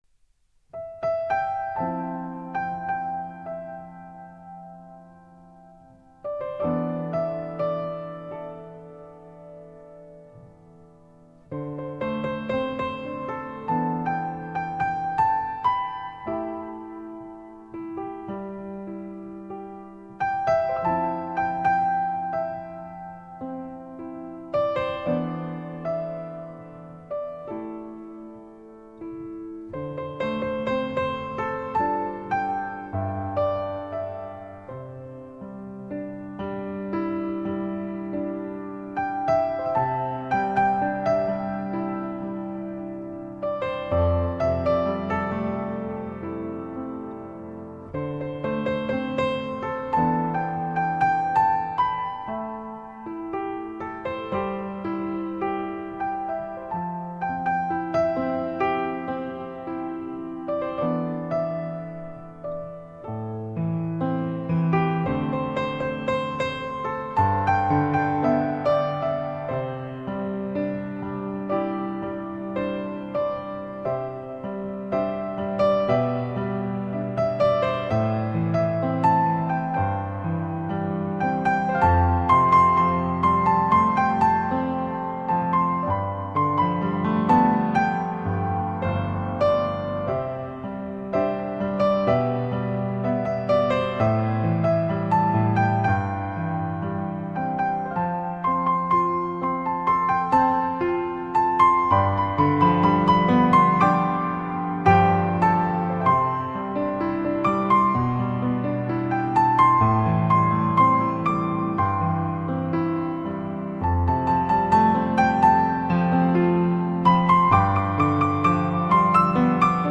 一种淡淡的 浅浅的情绪在心头流淌 在指尖荡漾 在旋律中回响